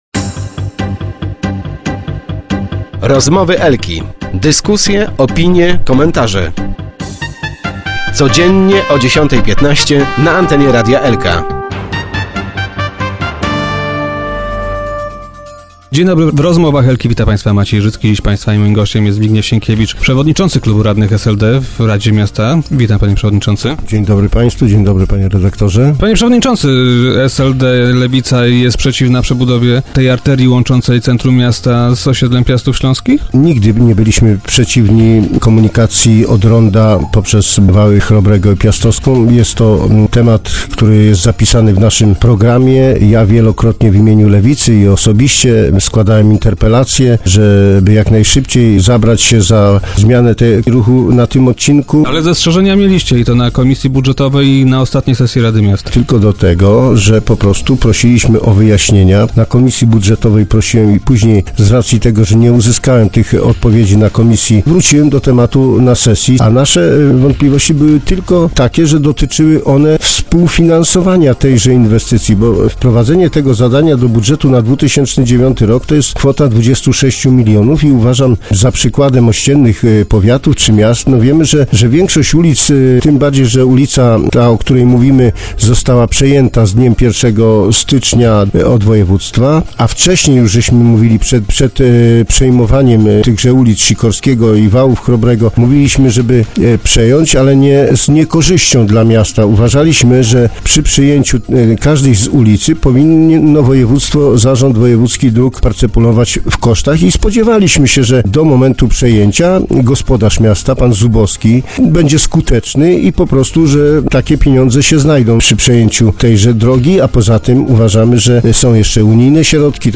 Sienkiewicz był dziś gościem Rozmów Elki.